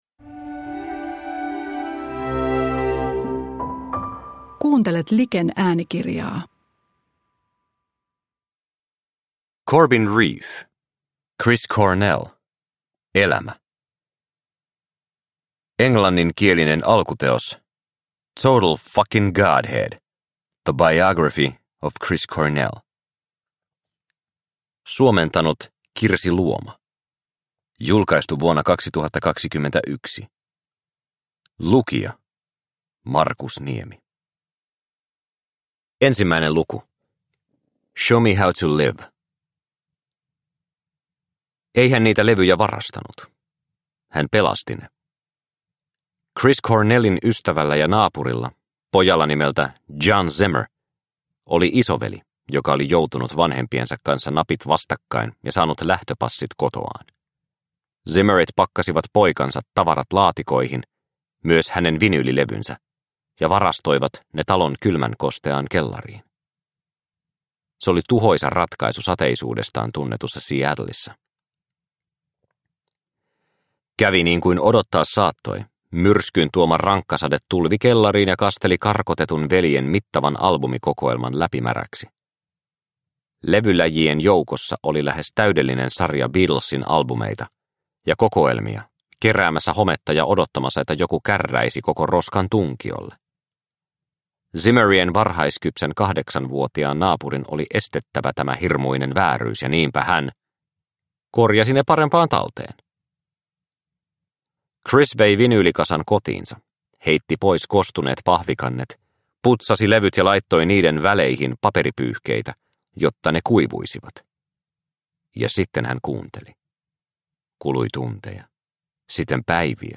Chris Cornell – Ljudbok – Laddas ner